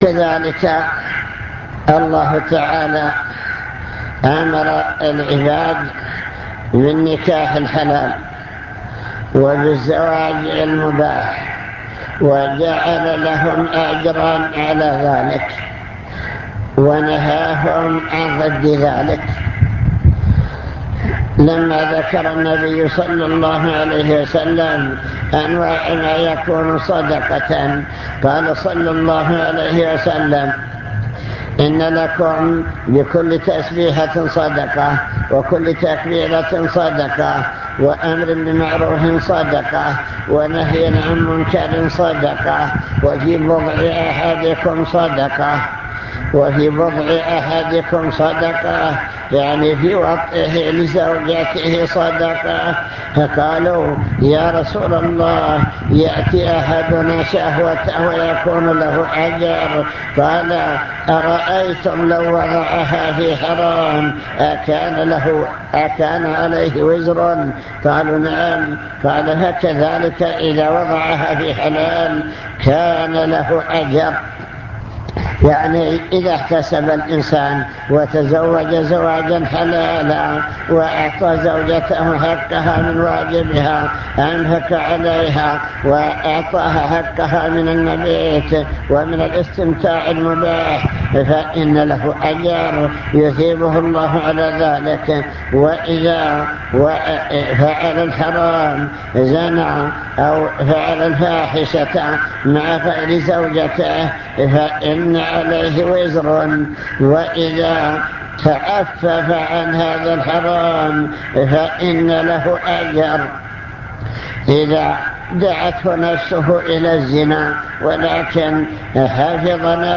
المكتبة الصوتية  تسجيلات - محاضرات ودروس  محاضرة بعنوان من يرد الله به خيرا يفقهه في الدين